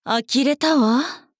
大人女性│女魔導師│リアクションボイス│商用利用可 フリーボイス素材 - freevoice4creators
呆れる